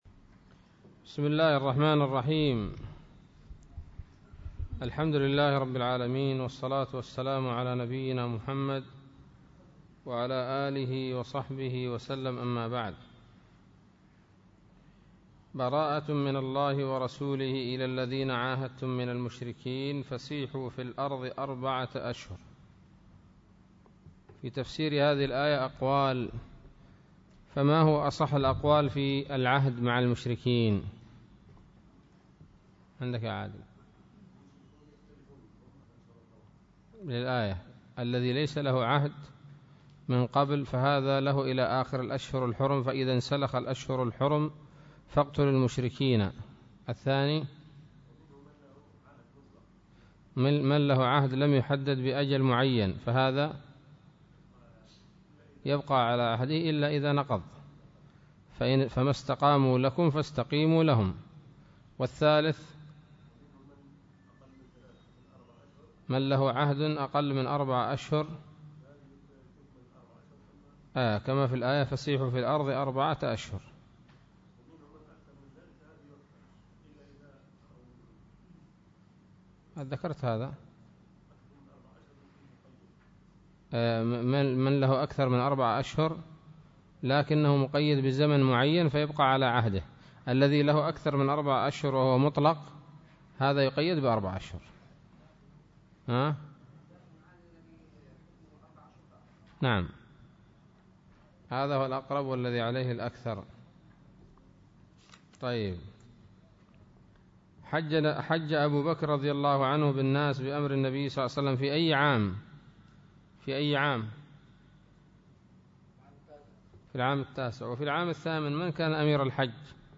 الدرس الثالث من سورة التوبة من تفسير ابن كثير رحمه الله تعالى